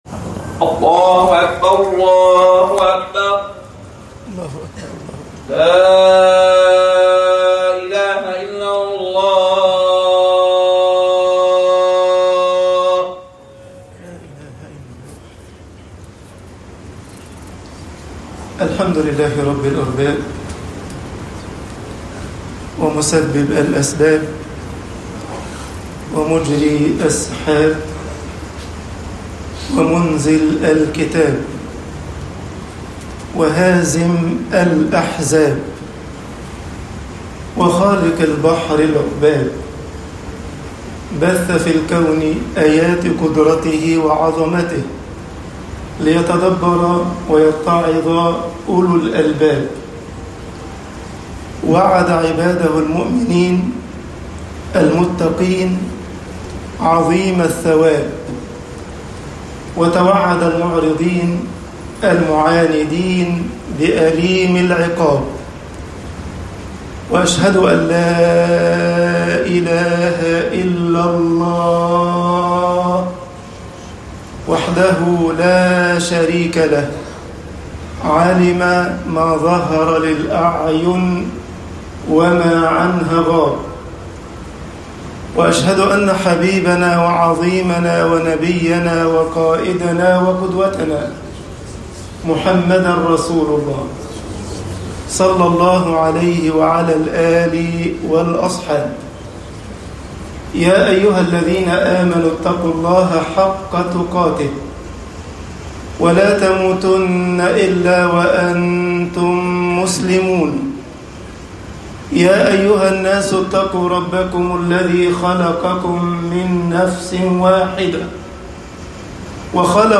خطب الجمعة - مصر الاستجابة لله حياة طباعة البريد الإلكتروني التفاصيل كتب بواسطة